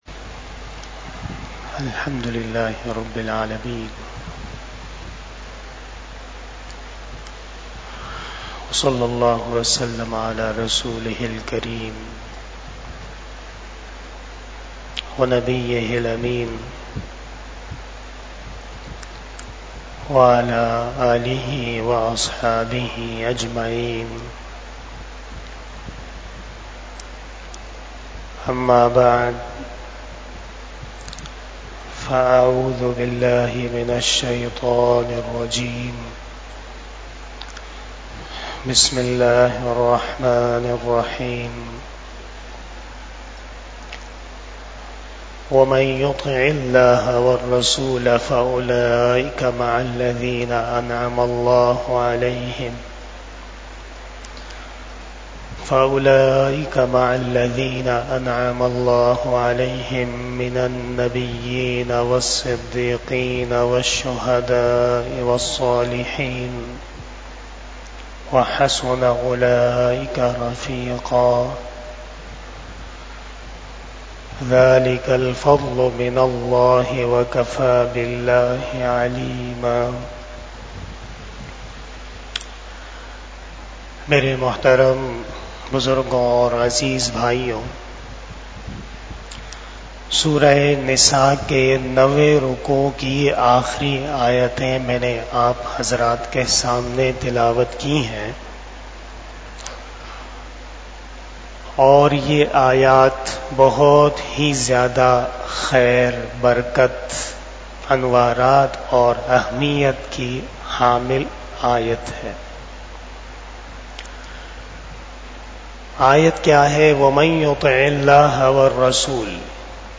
بیان شب جمعۃ المبارک